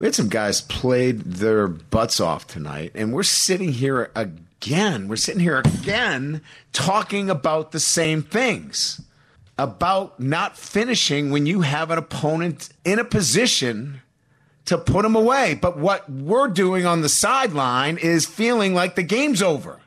Kelly was visibly upset in his post-game news conference. His water bottle nearly tipped over as he slammed his fist on the news conference table: